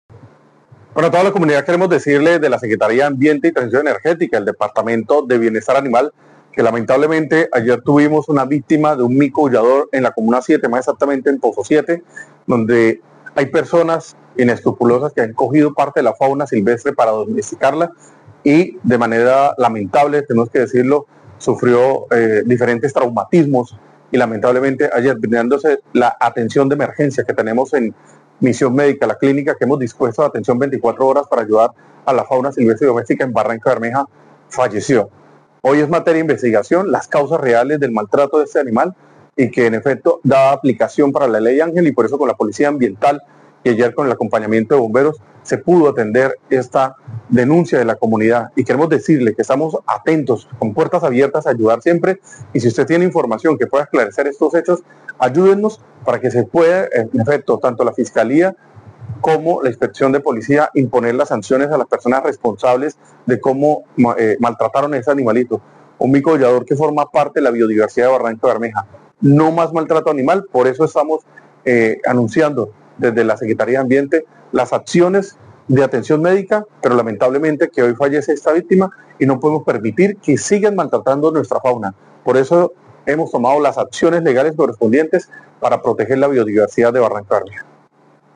Leonardo Granados, secretario de Ambiente y Transición Energética de Barrancabermeja